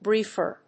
/ˈbrifɝ(米国英語), ˈbri:fɜ:(英国英語)/